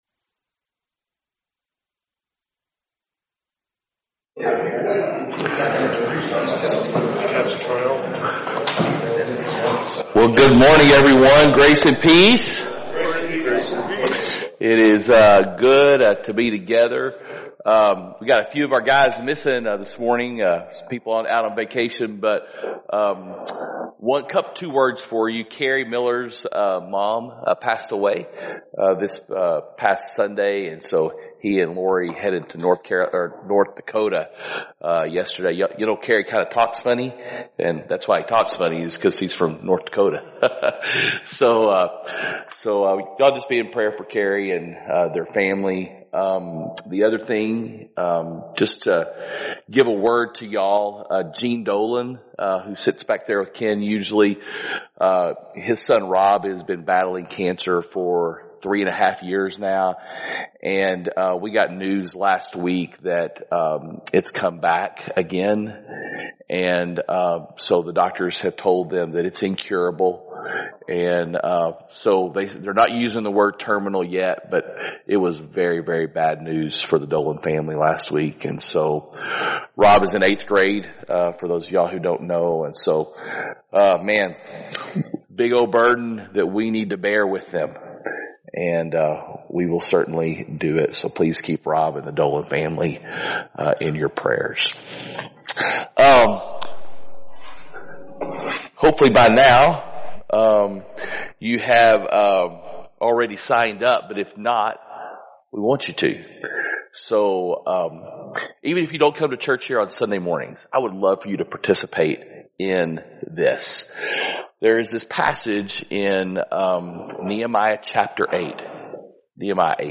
Men’s Breakfast Bible Study 9/17/24